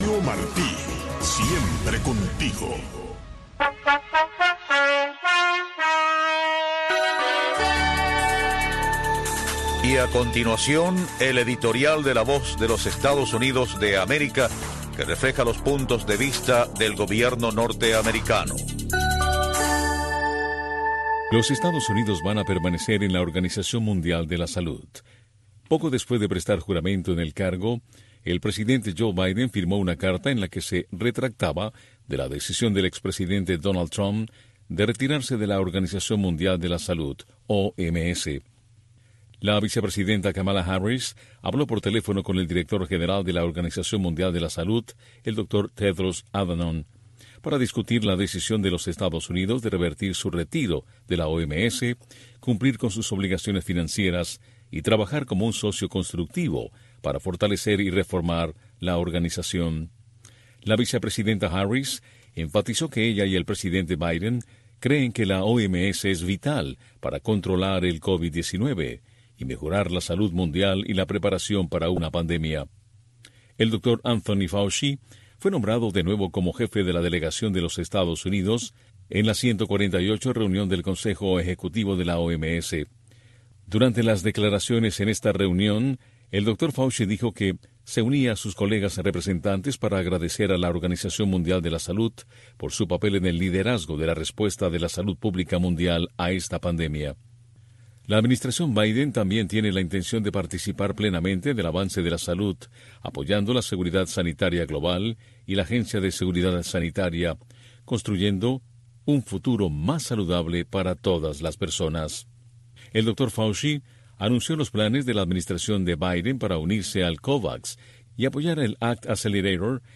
una revista de entrevistas